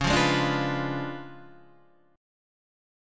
C# 7th Flat 9th